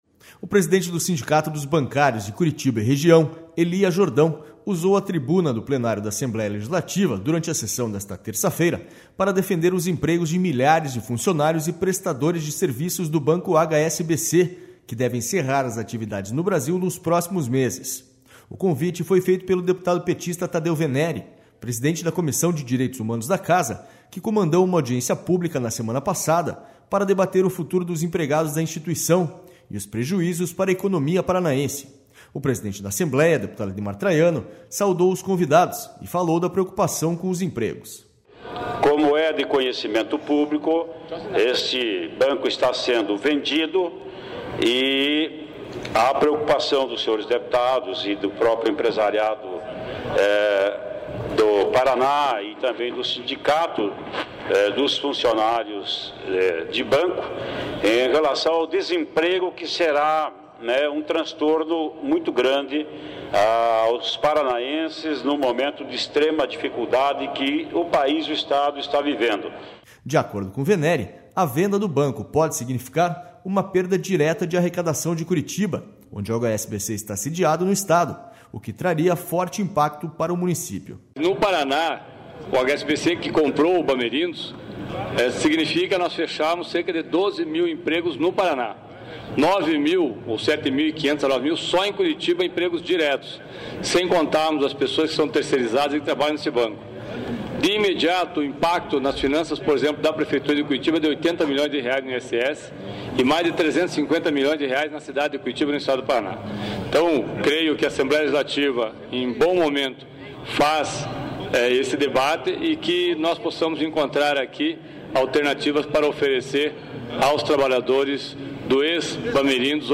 O presidente da Assembleia, deputado Ademar Traiano, saudou os convidados e falou da preocupação com os empregos.//
SONORA ADEMAR TRAIANO
SONORA TADEU VENERI